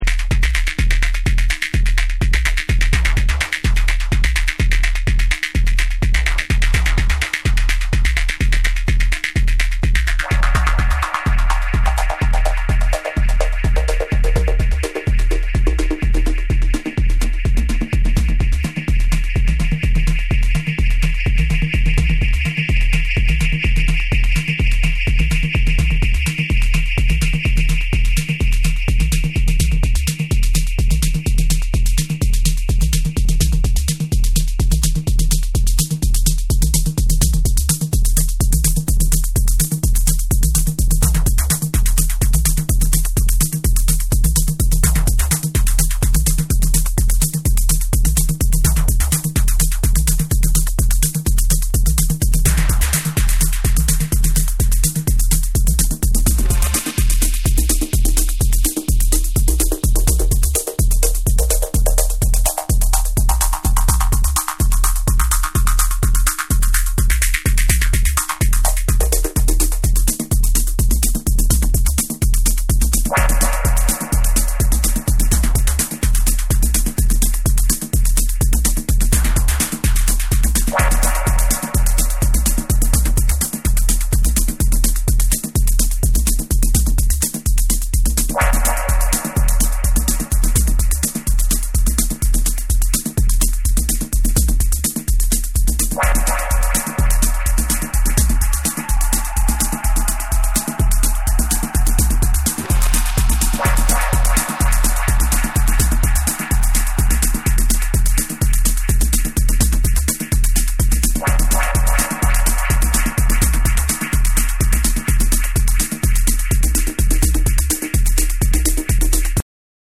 緻密なプログラミングと躍動感あるビートで展開するテクノと浮遊感のある幻想的なアブスト・ブレイクビーツを収録。
JAPANESE / TECHNO & HOUSE / BREAKBEATS